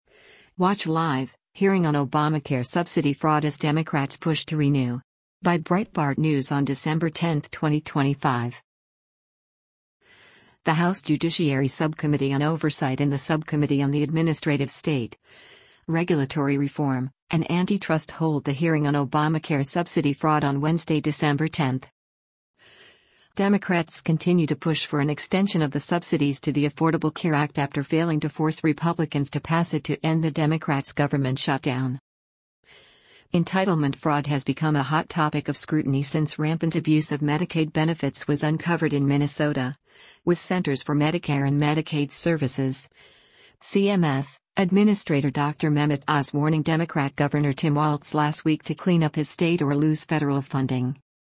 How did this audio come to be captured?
The House Judiciary Subcommittee on Oversight and the Subcommittee on the Administrative State, Regulatory Reform, and Antitrust hold a hearing on Obamacare subsidy fraud on Wednesday, December 10.